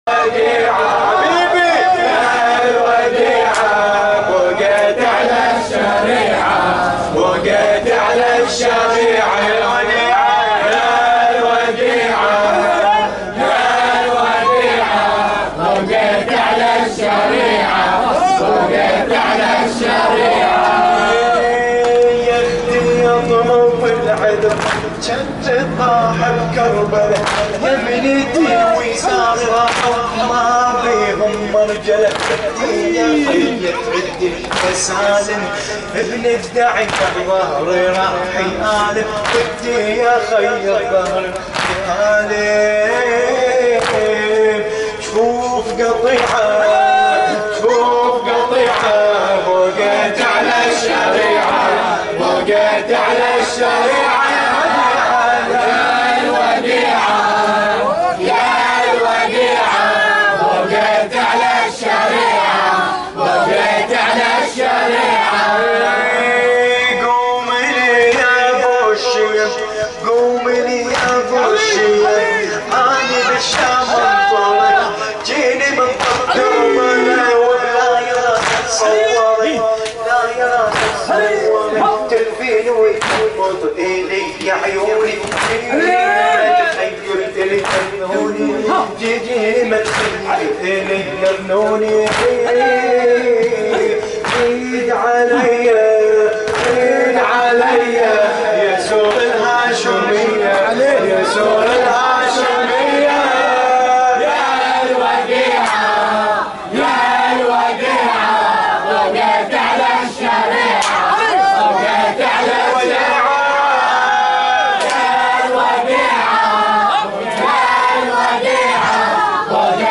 تغطية شاملة: موكب الأشبال لوفاة السيدة زينب ع 1440هـ
الرادود